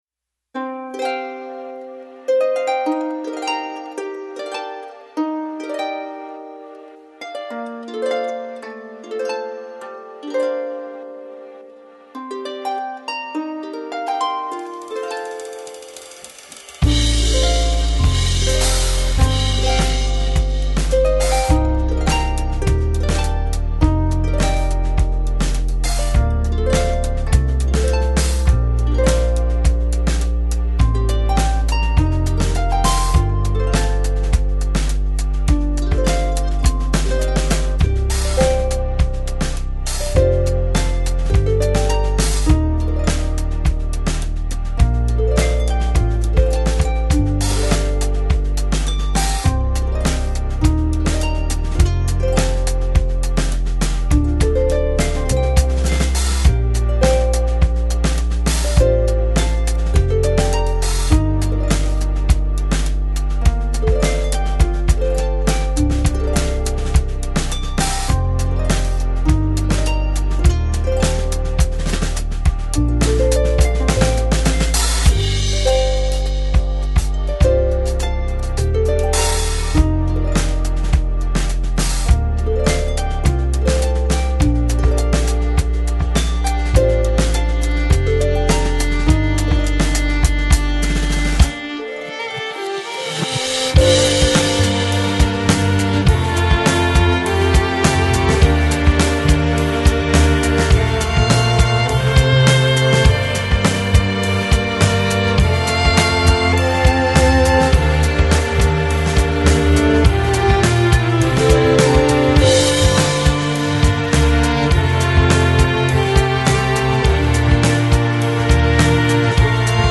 Electronic, Lounge, Chill Out, Downtempo Год издания